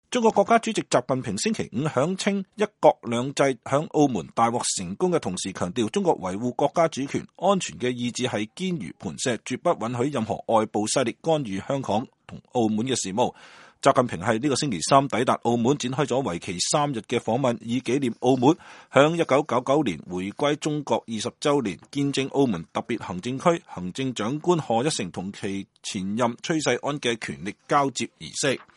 中國國家主席習近平在澳門為新任澳門行政長官賀一誠的就職儀式上講話。